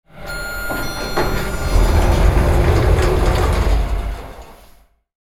Elevator Ding And Sliding Doors Sound Effect
This sound effect captures the opening or closing of elevator sliding doors with a clear ding-dong tone. It delivers a clean, realistic lift ambience ideal for videos, games, and apps. Use it to enhance scenes with authentic elevator motion and a crisp arrival alert.
Elevator-ding-and-sliding-doors-sound-effect.mp3